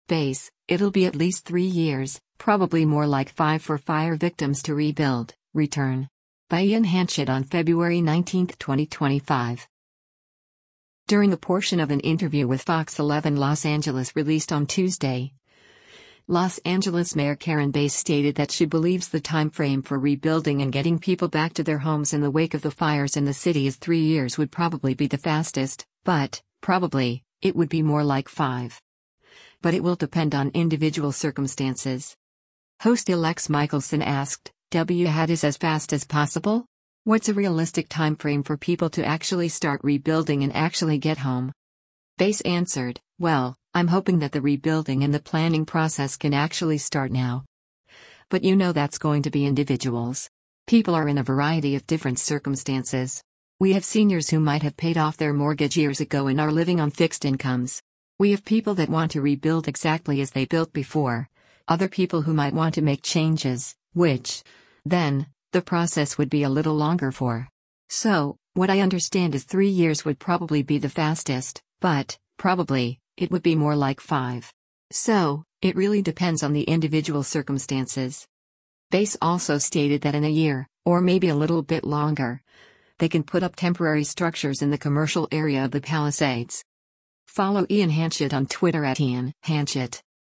During a portion of an interview with FOX 11 Los Angeles released on Tuesday, Los Angeles Mayor Karen Bass stated that she believes the timeframe for rebuilding and getting people back to their homes in the wake of the fires in the city “is three years would probably be the fastest, but, probably, it would be more like five.”